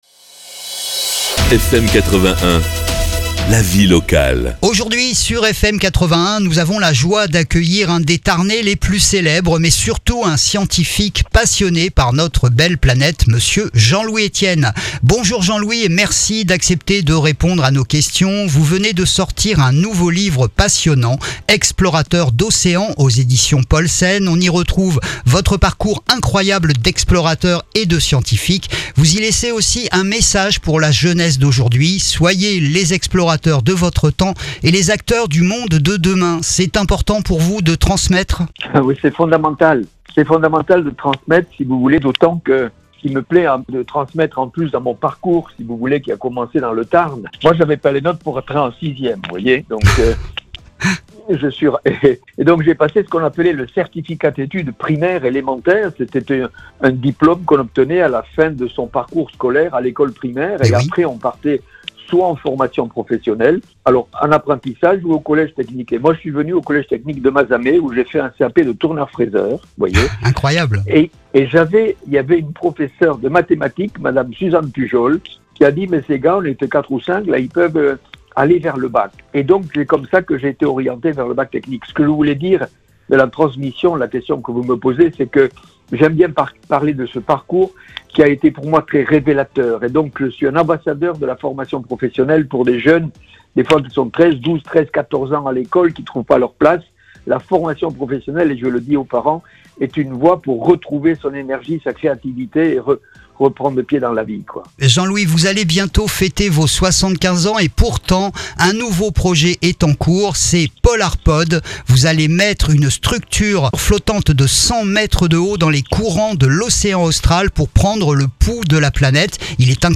...est notre invité cette semaine pour nous parler de son dernier livre !